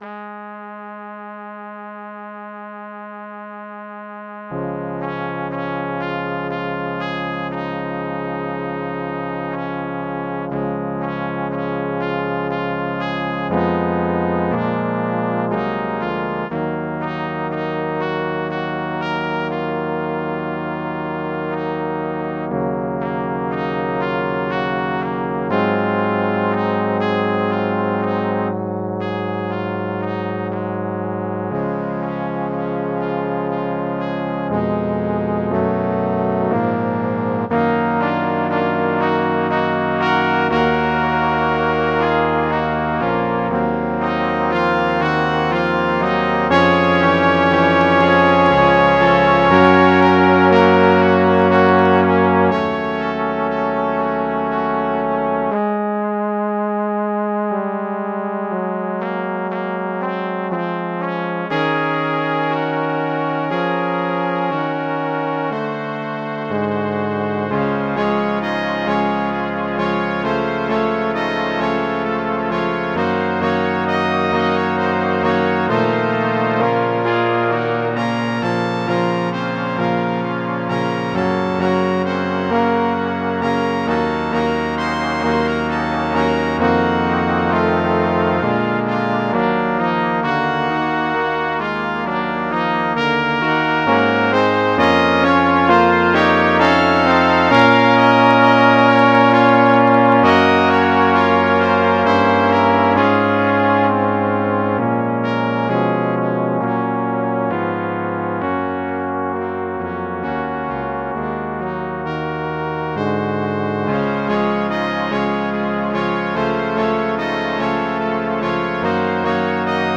Music for Brass
A delightfully thoughtful piece in reflective mood.